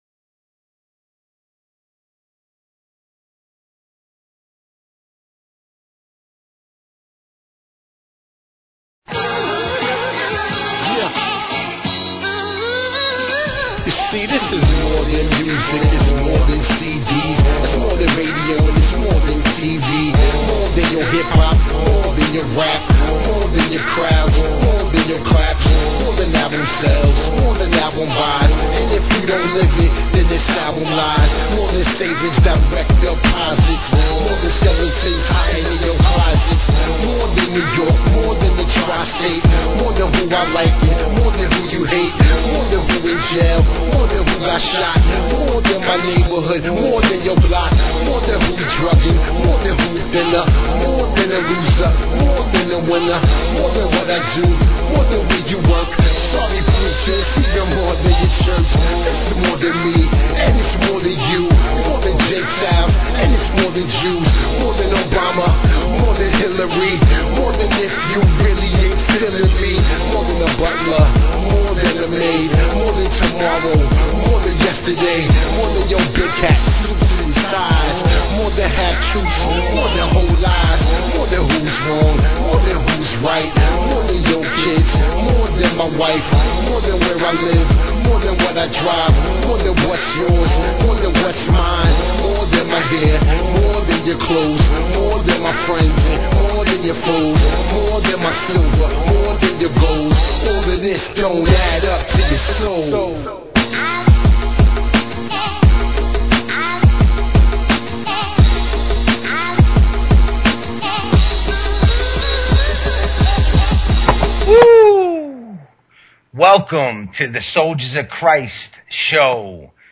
Talk Show Episode, Audio Podcast, Soldiers_of_Christ and Courtesy of BBS Radio on , show guests , about , categorized as
PREACHIN THE WORD WITH CHRISTIAN HIP HOP AND SOUL!
Spreading the good word; plus hip hop and soul inspired by the Gospel!